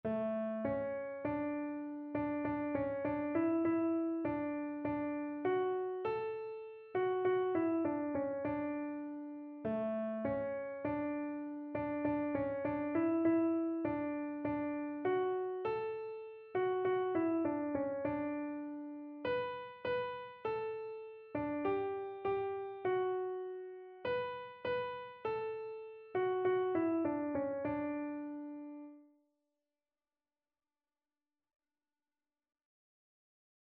Once In Royal David's City - D Major
This beautiful, reflective Christmas Carol is in AAB form.